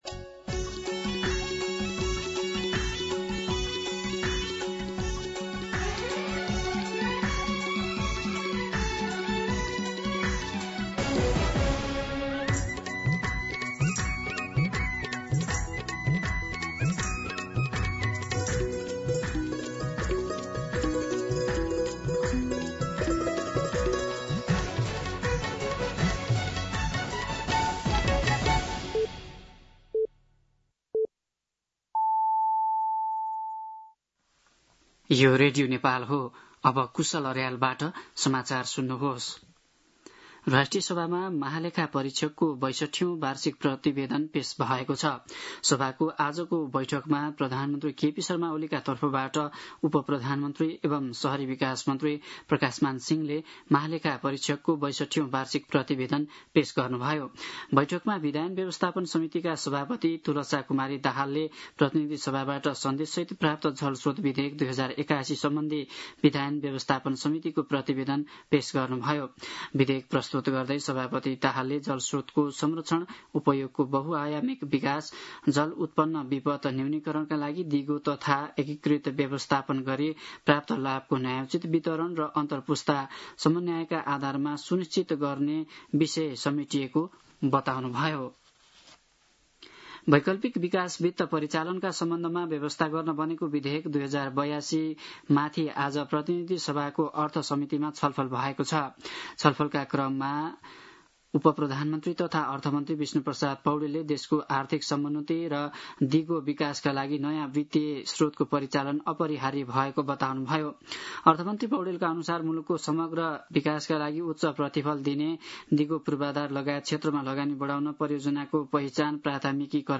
दिउँसो ४ बजेको नेपाली समाचार : २३ साउन , २०८२
4-pm-Nepali-News-.mp3